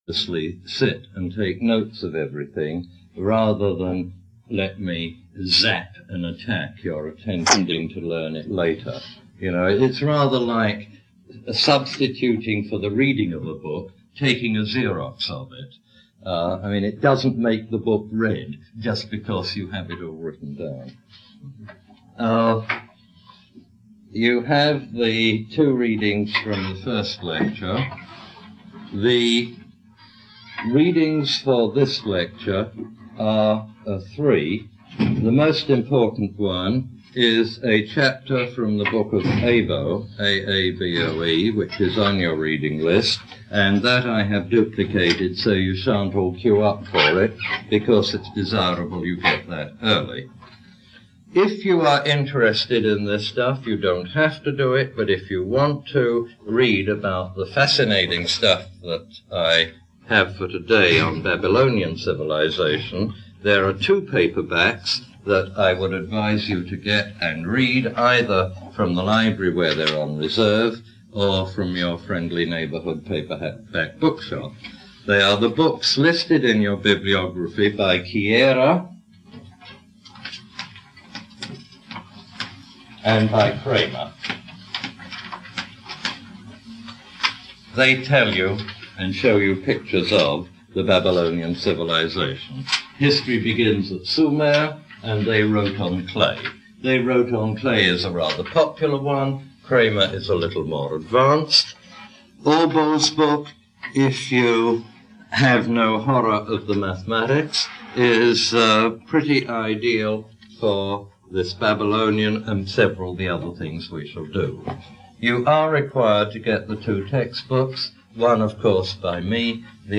Derek de Solla Price “Neolithic to Now” Lecture #2